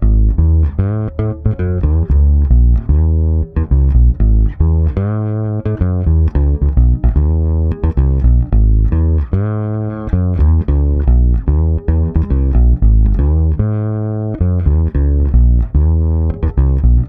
-AL AFRO A#.wav